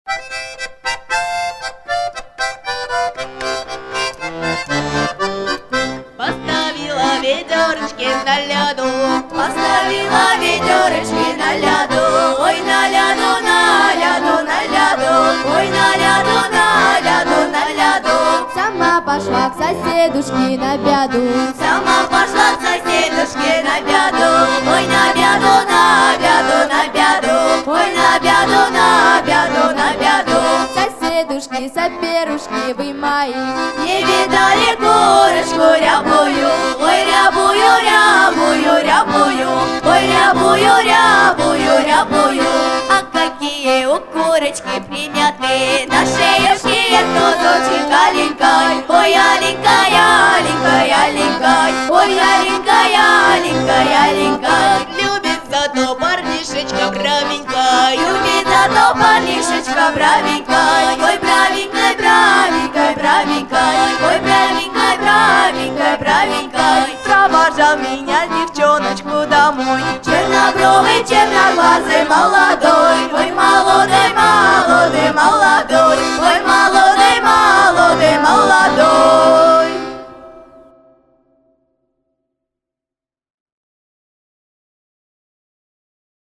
ансамбль